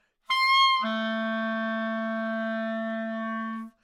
单簧管单音（吹得不好） " 单簧管 A3 攻击力差舌头阻挡
描述：在巴塞罗那Universitat Pompeu Fabra音乐技术集团的goodsounds.org项目的背景下录制。单音乐器声音的Goodsound数据集。
标签： 纽曼-U87 单簧管 单注 多样本 A3 好声音
声道立体声